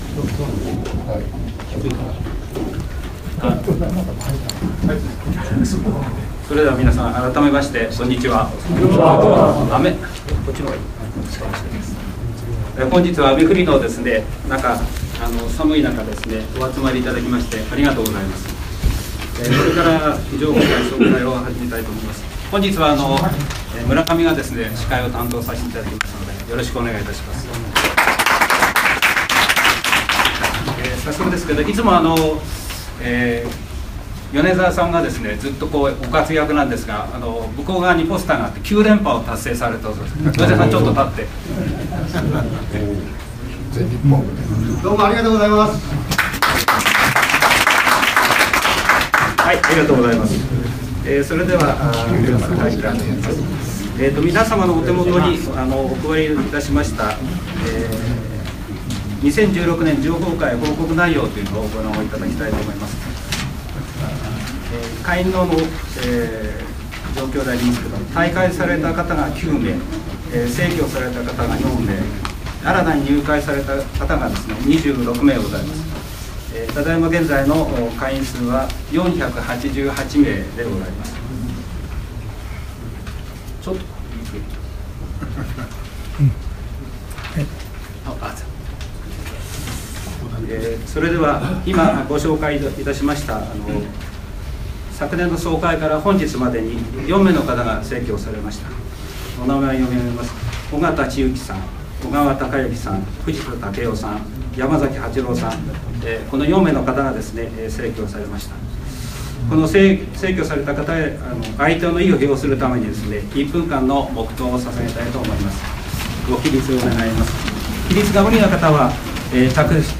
_ 第30回東芝情朋会総会を、去る11月11日（金曜日）会員80名の方々のご出席を得て、TCS本社の会議室に於いて開催しました。 事業報告・会計報告・会則改定などの議事事項が報告され承認されました。